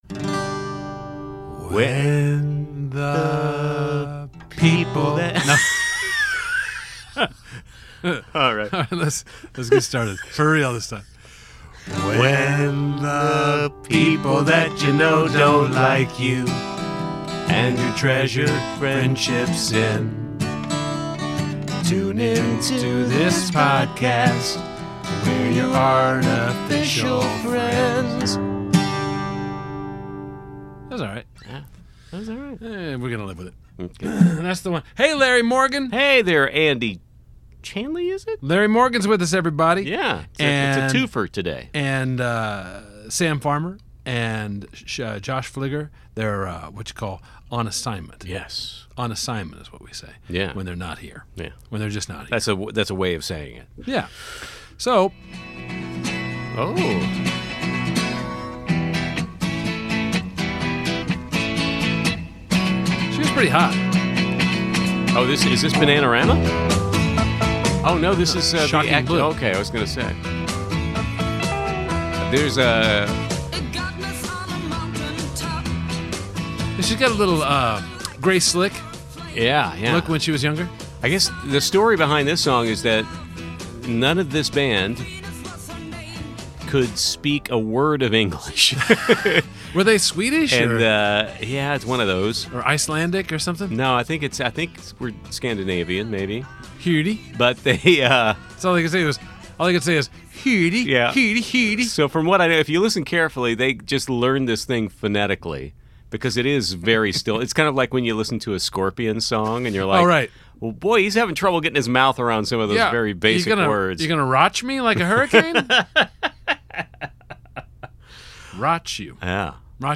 Hear two real humans shoot the shit for 45 minutes right here, folks.